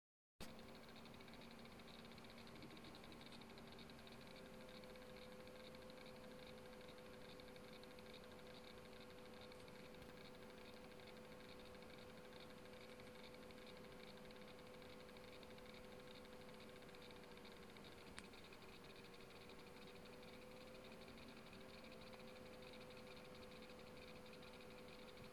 Terkotanie dysku twardego (HDD)
Dziś zauważyłem, że dysk terkocze.
Bywa że terkotanie ustaje ale zazwyczaj szybko powraca.
Brzmi to tak (jest bardzo ciche)
Wygląda na to, że to terkocze ramię głowicy, dźwięk jest identyczny gdy zapisuję plik ale zmienia się rytm z jednostajnego terkotania na zmienny, charakterystyczny dla głowicy HDD przeskakującej pomiędzy obszarami na talerzu.